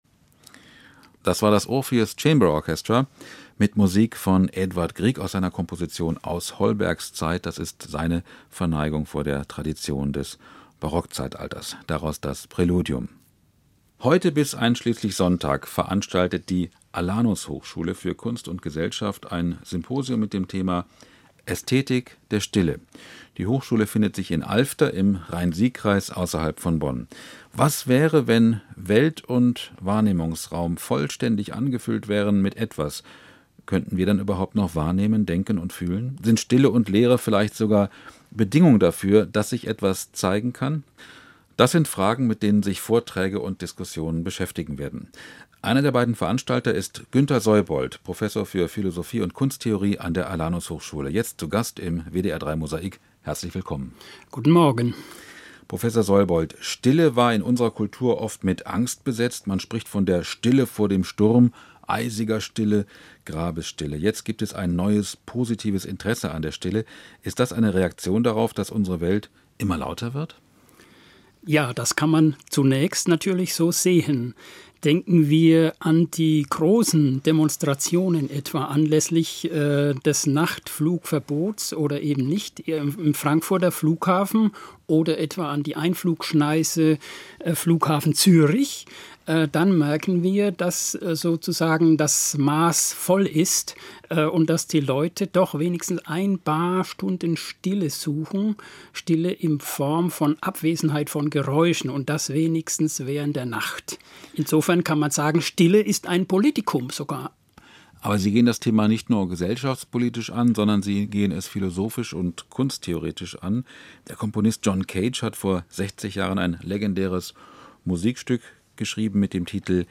Live-Interview zum Symposium „Ästhetik der Stille”